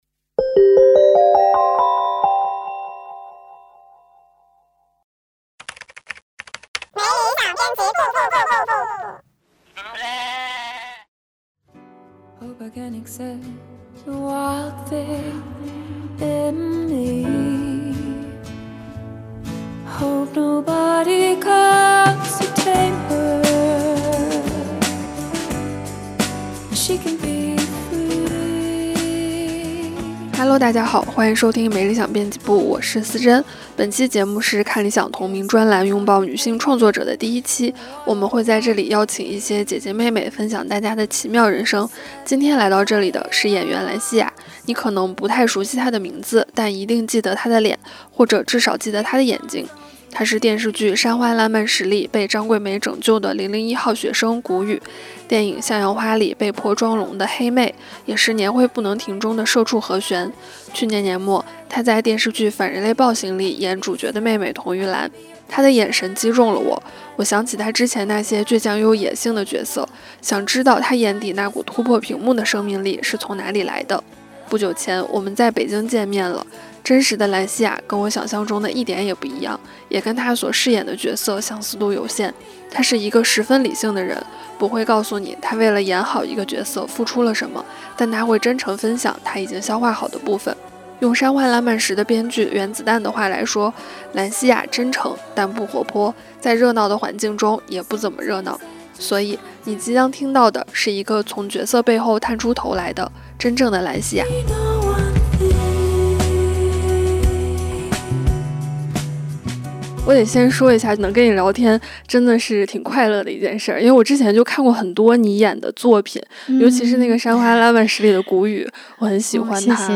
Vol.220 对话演员兰西雅：人生是大不了就撤退